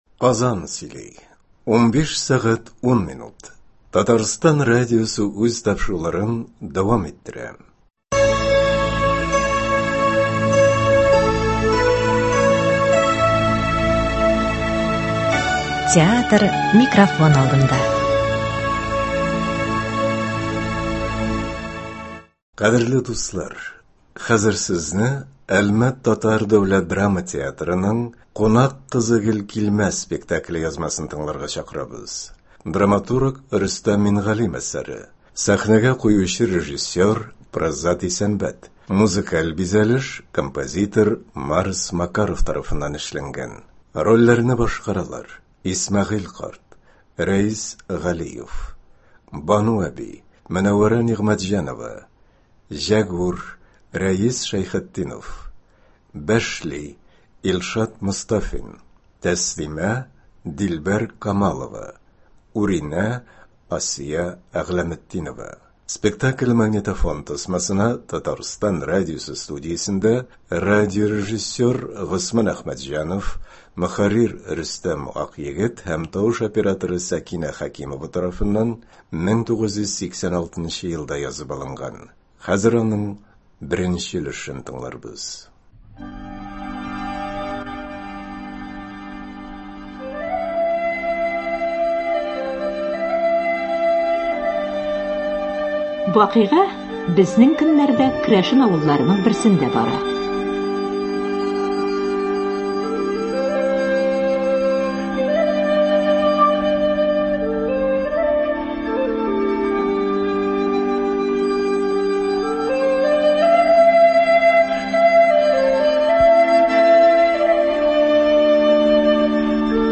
Спектакль магнитофон тасмасына Татарстан радиосы студиясендә